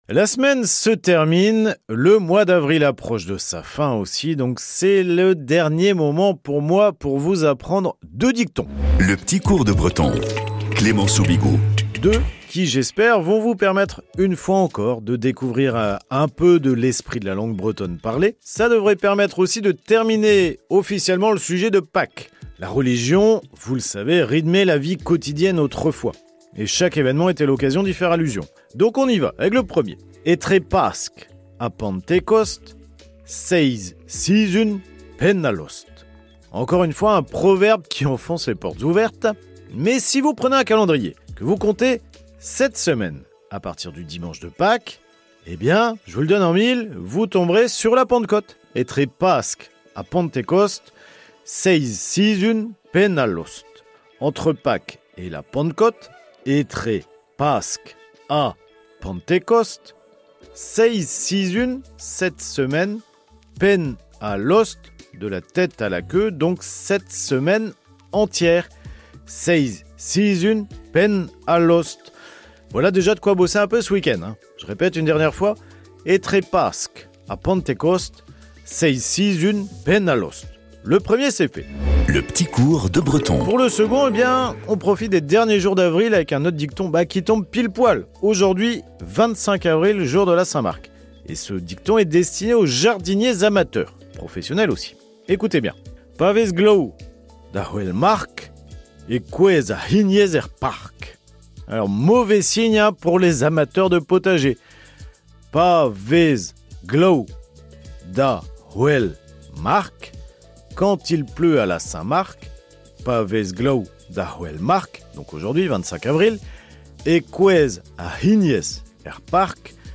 chronique quotidienne